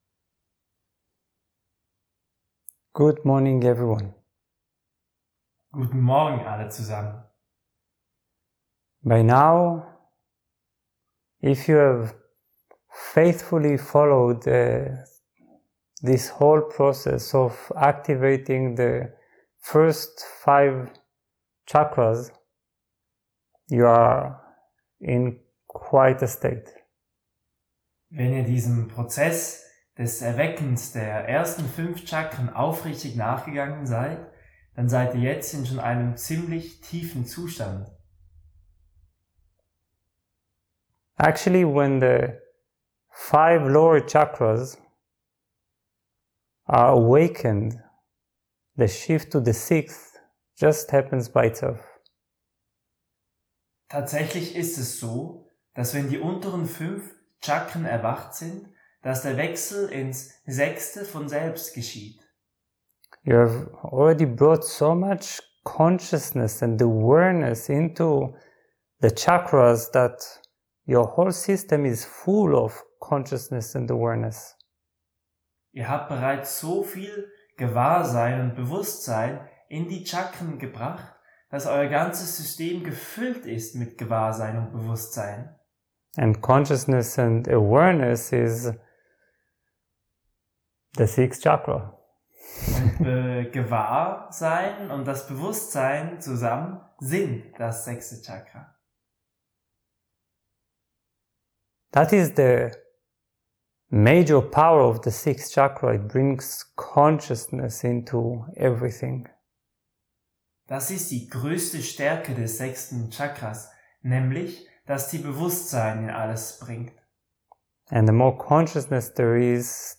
Meditation auf Deutsch